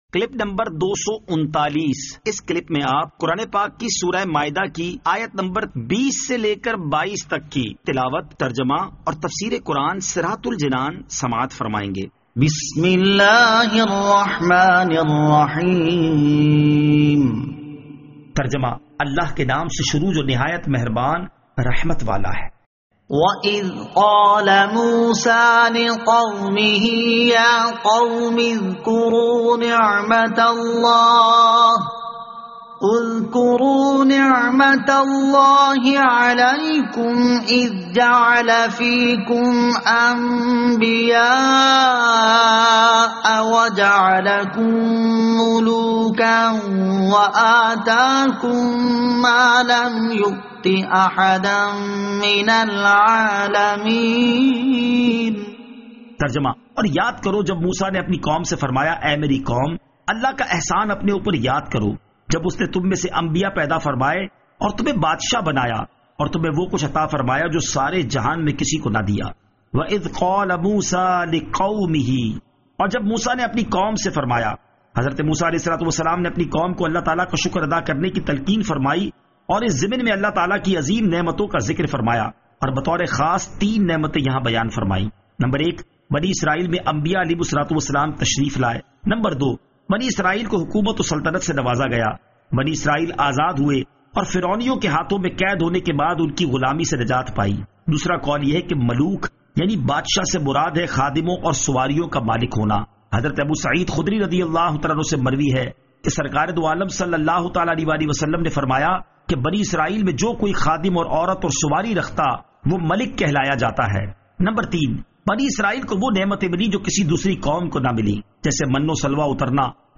Surah Al-Maidah Ayat 20 To 22 Tilawat , Tarjama , Tafseer